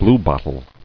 [blue·bot·tle]